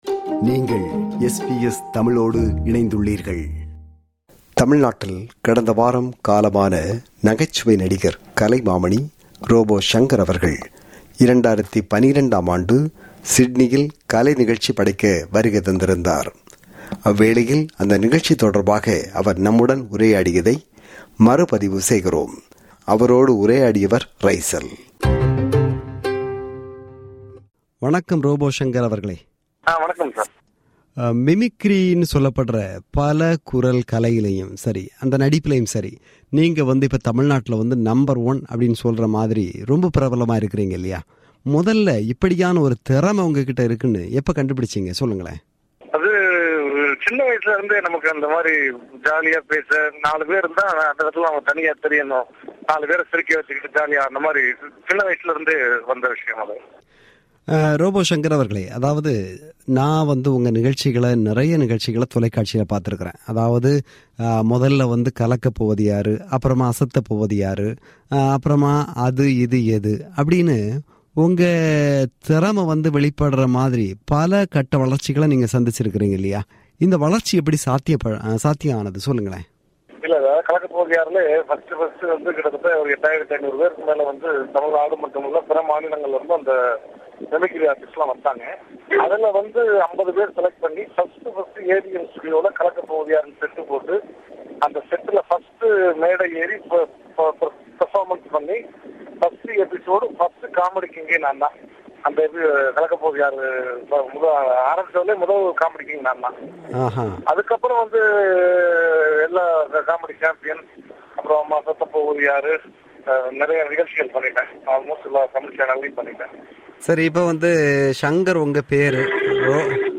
A 2012 conversation: Robo Shankar with SBS Tamil To hear more podcasts from SBS Tamil, subscribe to our podcast collection.